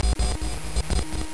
00020_Sound_satellite.mp3